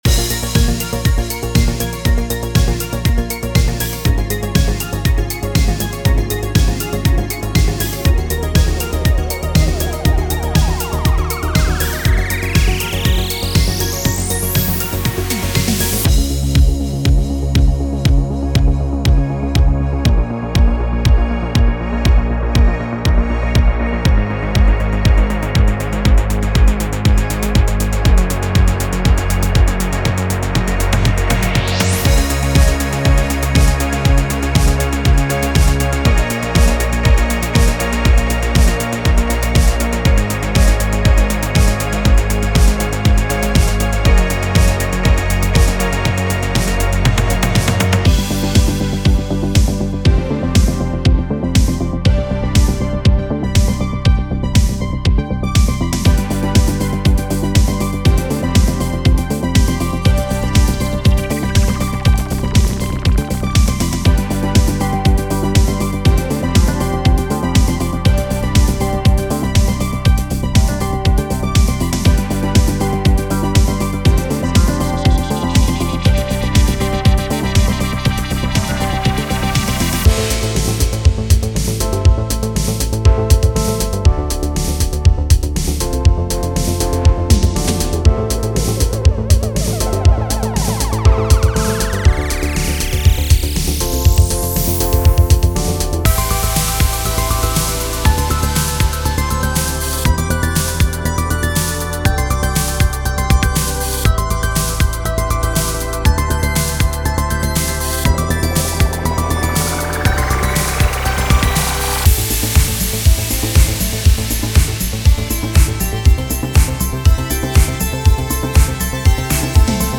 Synthwave / Retrowave